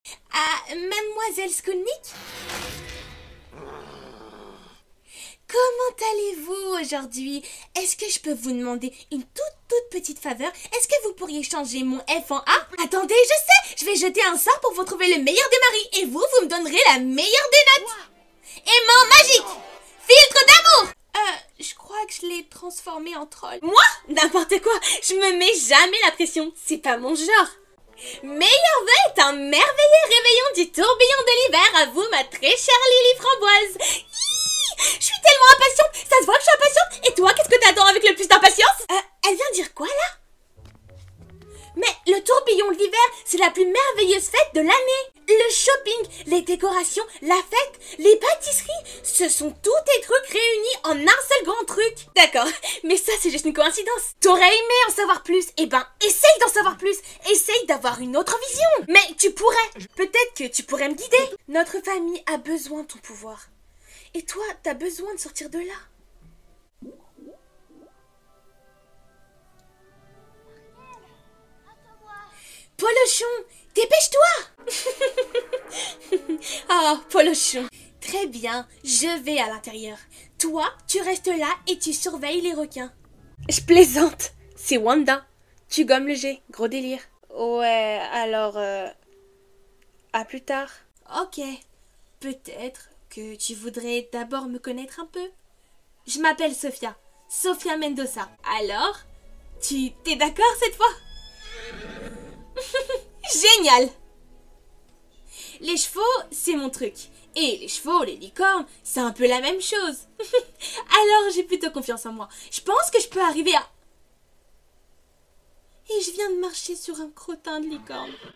Voix off
Piste audio bande démo doublage